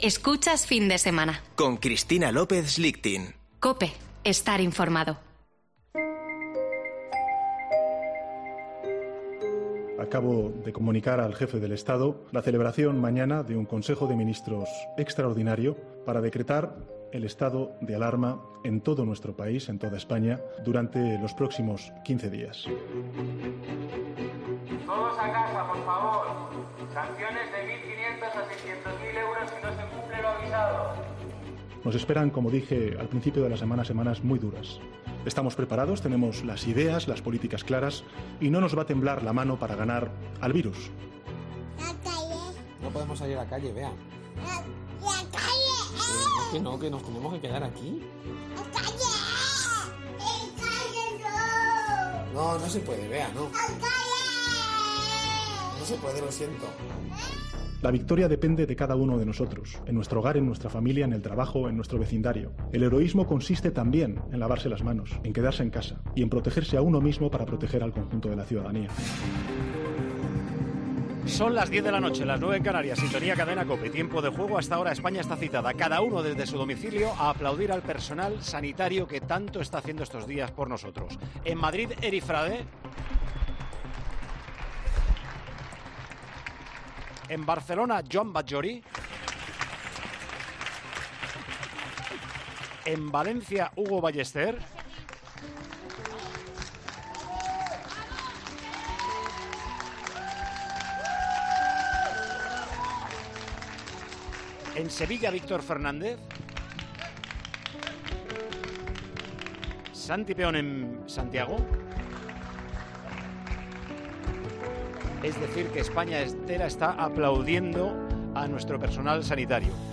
Cristina López Schlichting recuerda en su monólogo cómo la pandemia ha cambiado nuestras vidas y ha puesto en "solfa" nuestro orden social